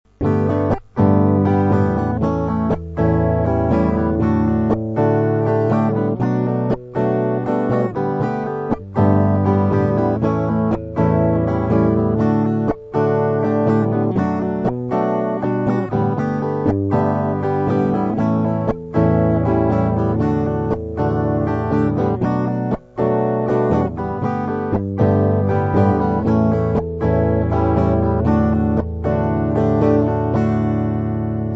Проигрыш (G - A - D - Hm7):